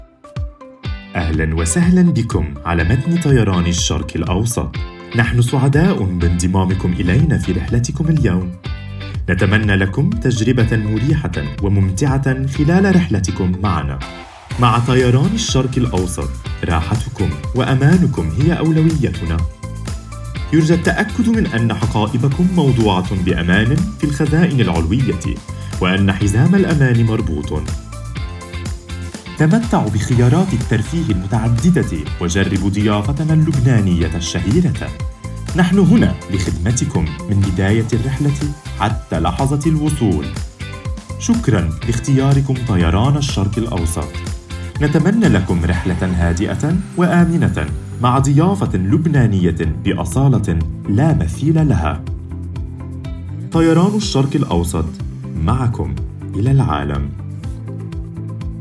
Arabic (Lebanon), Middle Eastern, Male, 20s-30s